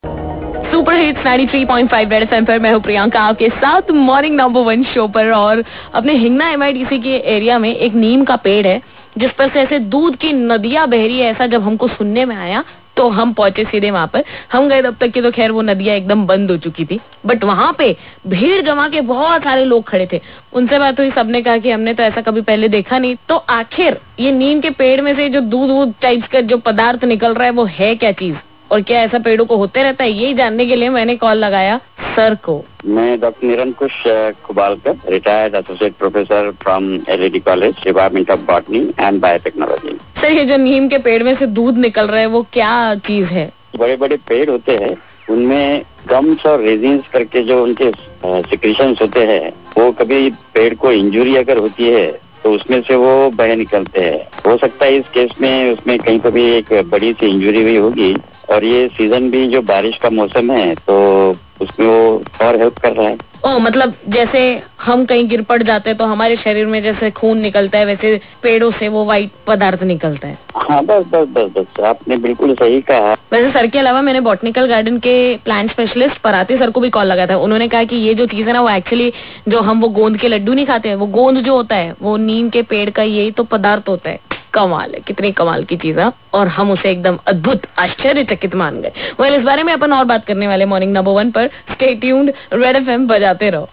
TALKING TO THE BOTANIST ABOUT THE AMAZING TREE